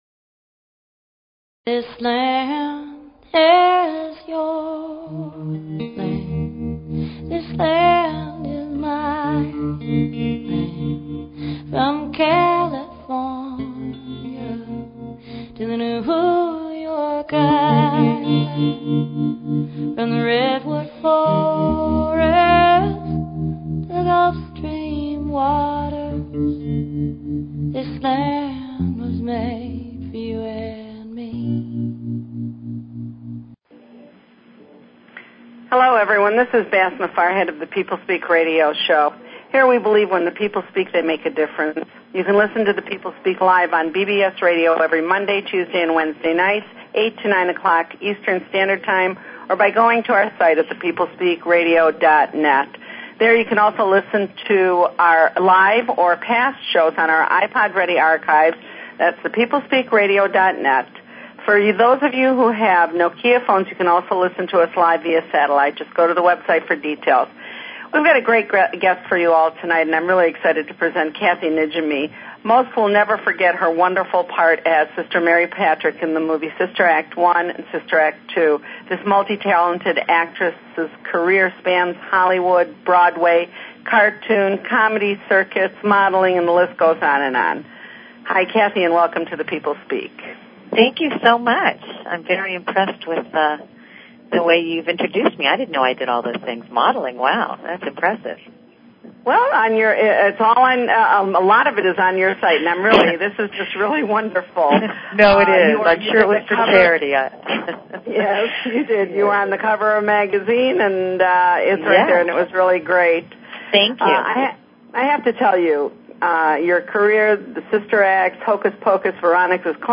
Talk Show Episode, Audio Podcast, The_People_Speak and Kathy Najimy on , show guests , about , categorized as Comedy,Politics & Government,Society and Culture,TV & Film
Guest, Kathy Najimy